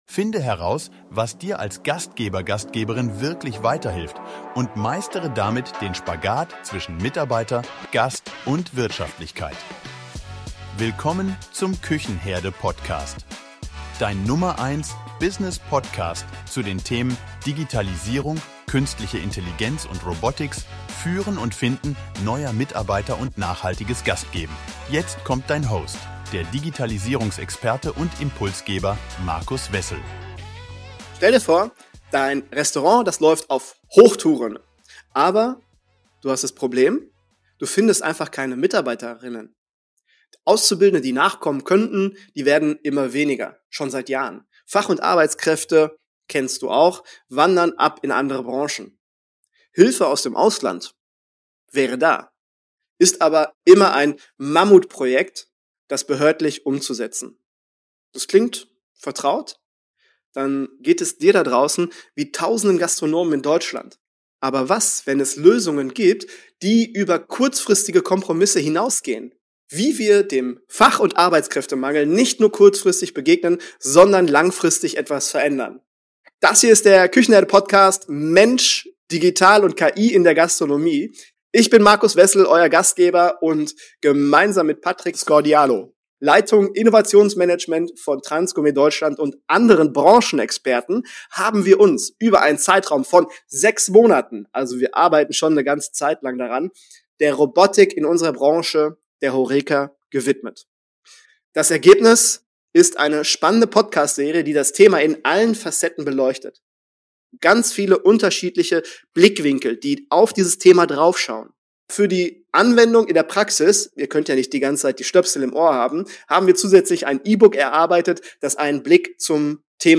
Robotics im Foodservice: Experten-Talk über die Zukunft von Robotern in der HoReCa ⋆ Küchenherde